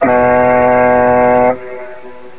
boat-horn.wav